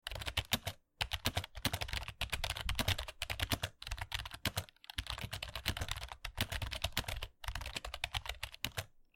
K55 Typing.mp3